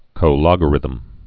(kōgə-rĭthəm, -lŏgə-)